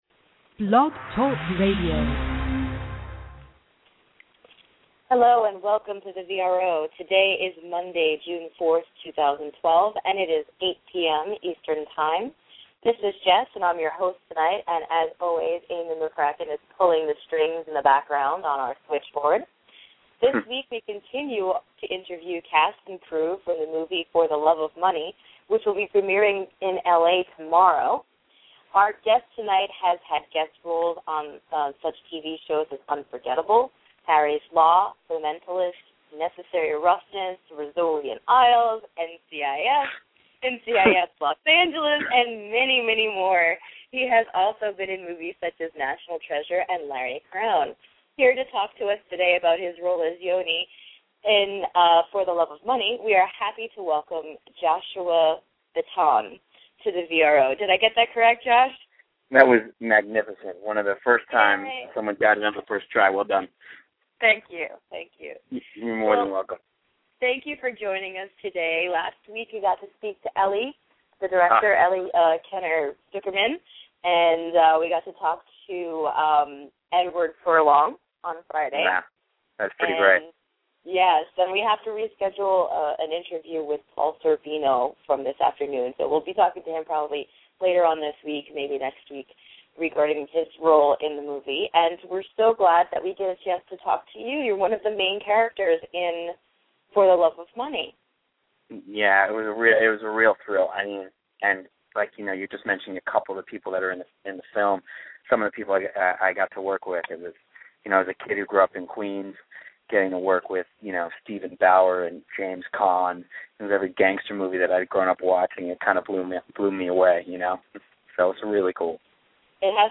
Joshua Bitton Interview